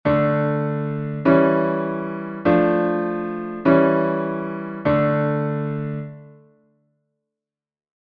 Das Wesen des Faux bourdon besteht in der fortgesetzten Begleitung des Cantus firmus mit der oberen Terz und Sexte, aber zu Anfang und Ende jeder Melodiephrase statt der Terz mit der Quinte und statt der Sexte mit der Oktave.
Faux bourdon (Einstein 1929)
faux-bourdon-einstein1929.mp3